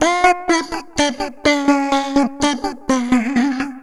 Index of /90_sSampleCDs/Sample Magic - Transmission-X/Transmission-X/transx loops - 125bpm